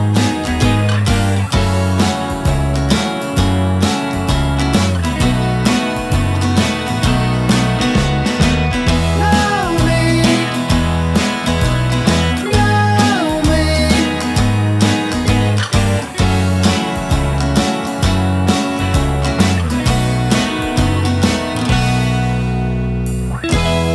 Minus Lead Guitar Indie / Alternative 3:32 Buy £1.50